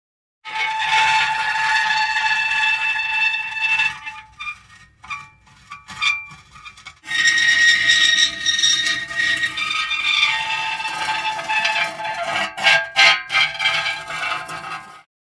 Противный скрежет металла